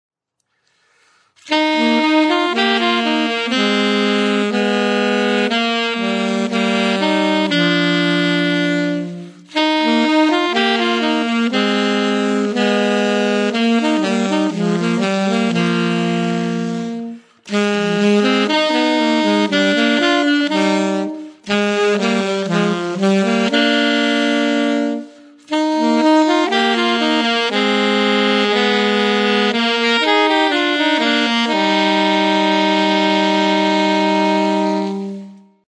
17 duetti per sassofono